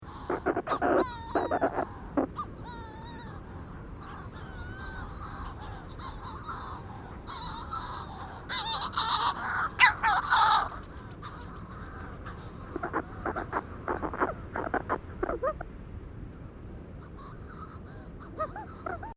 Manx Shearwater Recordings, July 2007, Co. Kerry, Ireland
burrows rhythmic